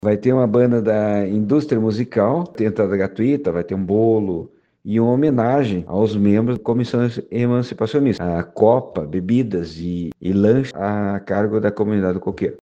Segundo o prefeito, João Paulo Beltrão dos Santos, o baile vai ocorrer no centro comunitário recém construído na localidade de Coqueiro e que serve para eventos de todo município. (Abaixo, sonora de João Paulo)